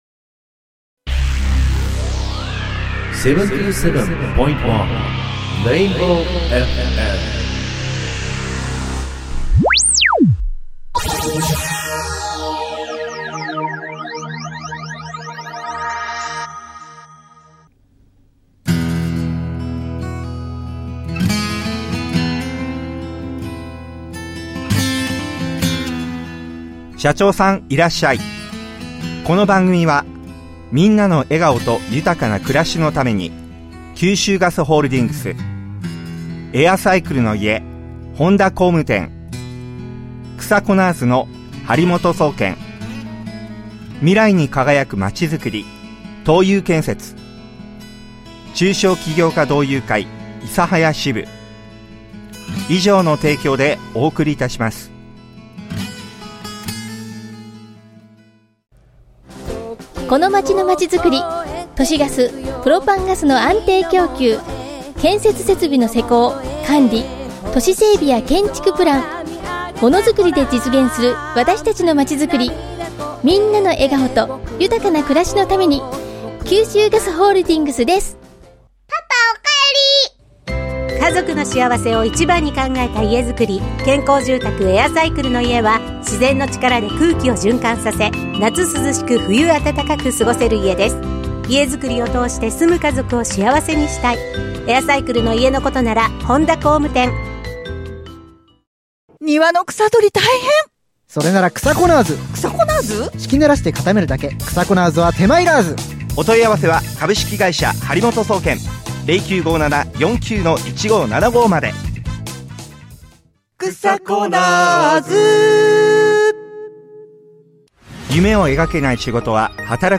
生放送を聴き逃した方はこちらより！